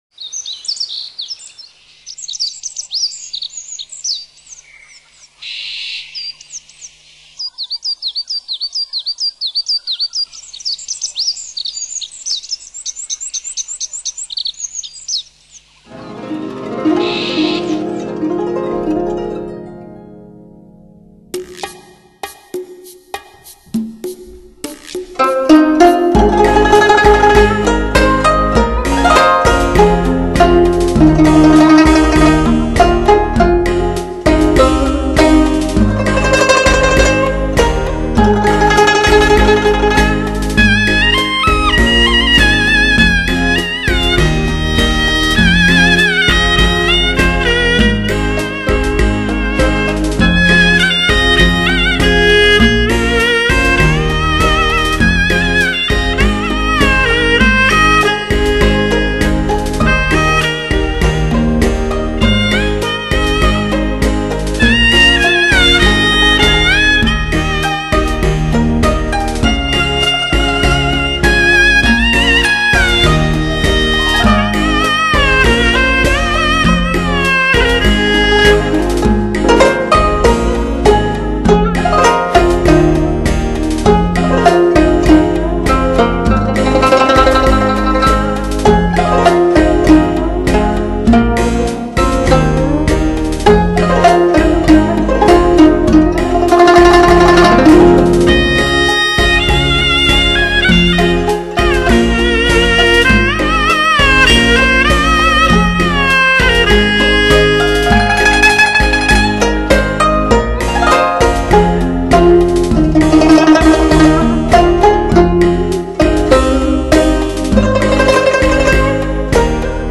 录制更是出色。